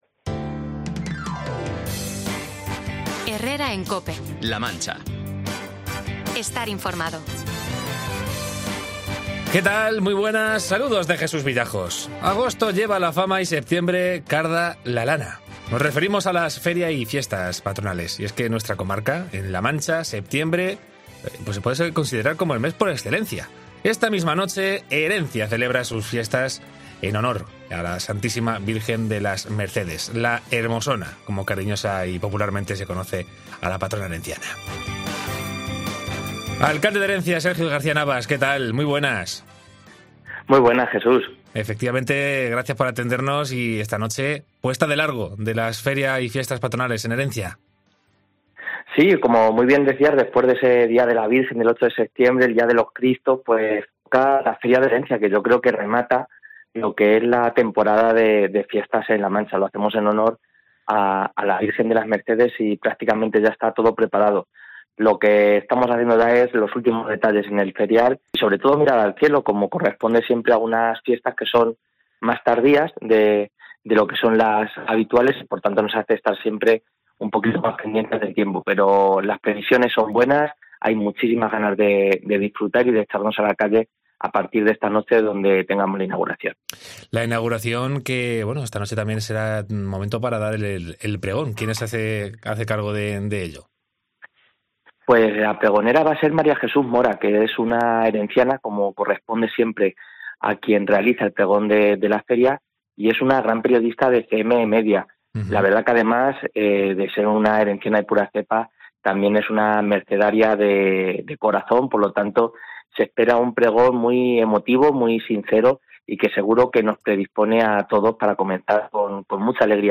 Entrevista a Sergio García-Navas, alcalde de Herencia, con motivo de la Feria y Fiestas